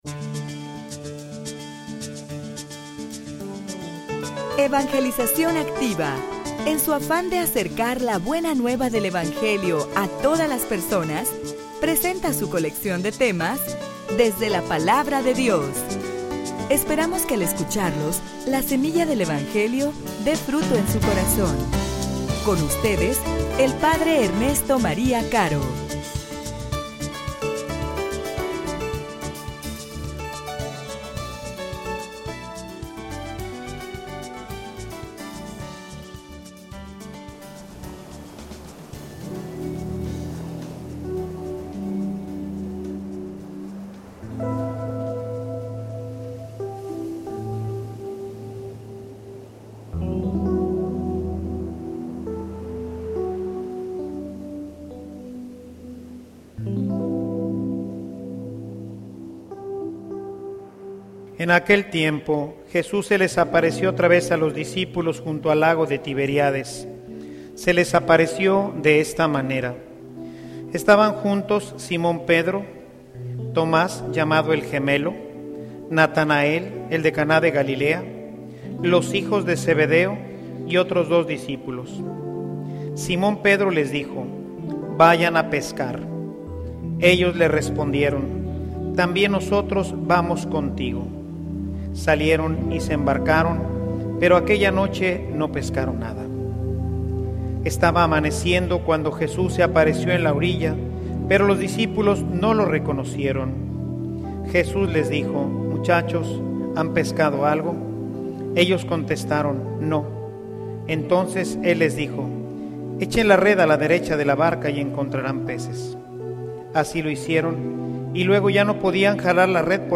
homilia_La_alegria_de_ser_martir.mp3